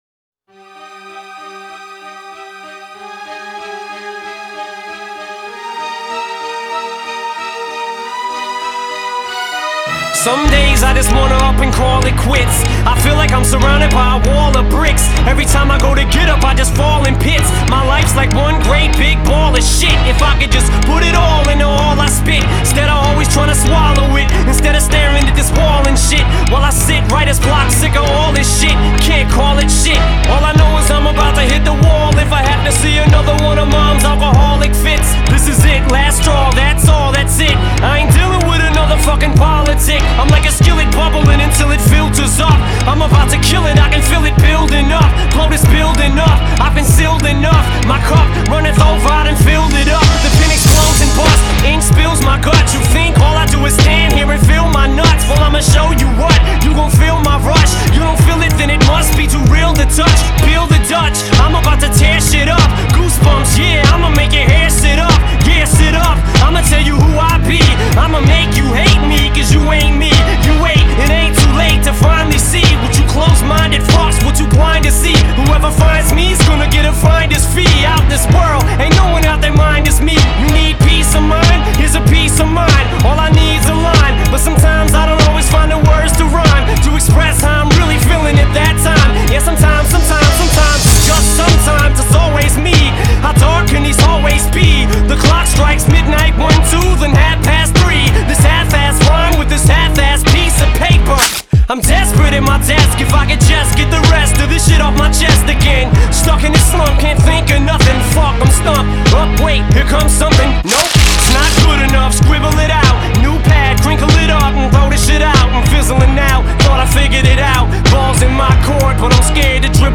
Genre : Hip-Hop, Rap